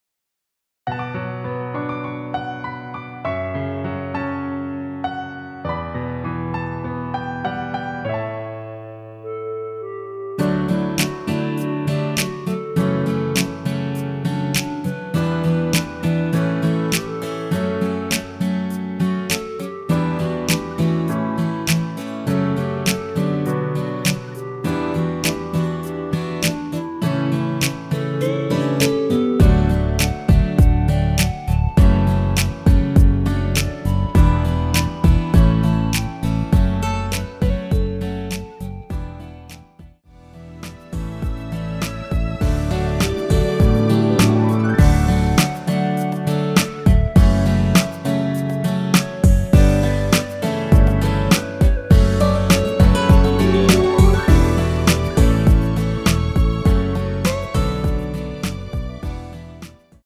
1절후 클라이 막스로 바로 진행되며 엔딩이 너무 길어 4마디로 짧게 편곡 하였습니다.
원키에서(-1)내린 멜로디 포함된 편곡 MR 입니다.
앞부분30초, 뒷부분30초씩 편집해서 올려 드리고 있습니다.